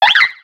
Cri de Larveyette dans Pokémon X et Y.